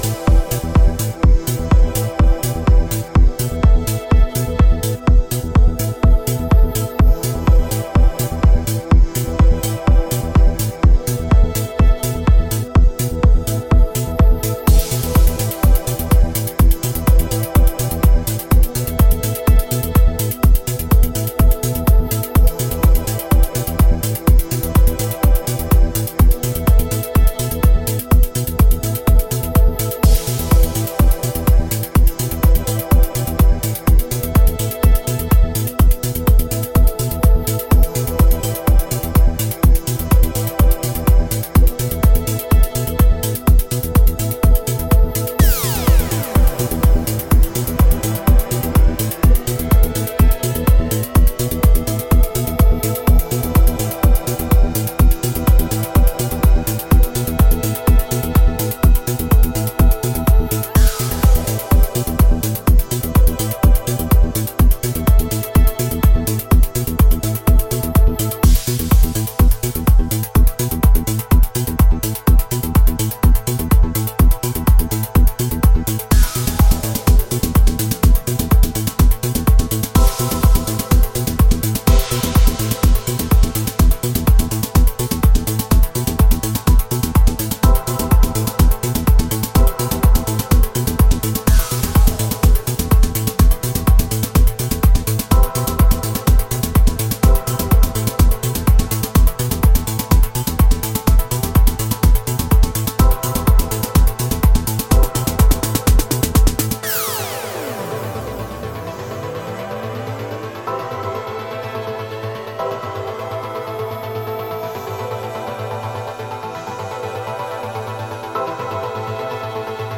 techno sound
fusion of string chords, reverse synth tricks & arpeggiation